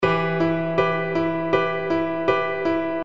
描述：解释：！创造你的钢琴！这里有所有的C调钢琴和弦。这里有所有C大调的钢琴和弦，用这些曲子你可以创造你自己的钢琴曲。构建你喜欢的钢琴旋律。玩得开心 )
Tag: 80 bpm Hip Hop Loops Piano Loops 516.84 KB wav Key : Unknown